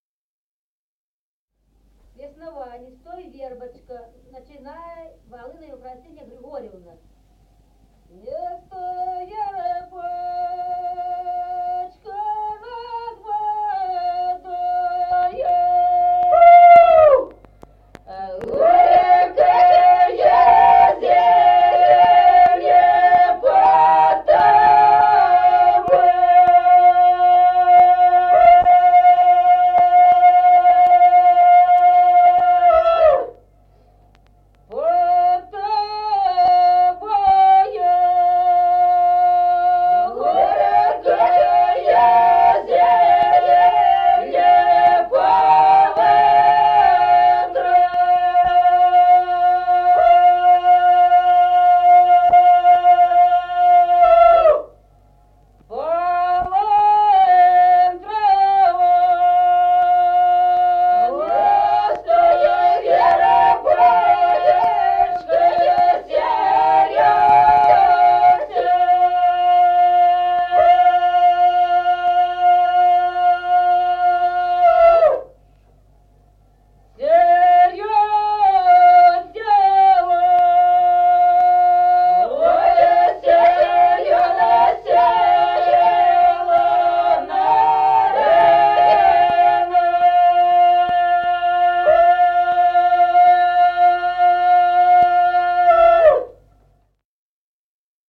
Народные песни Стародубского района «Не стой, вербочка», весняная девичья.
с. Остроглядово.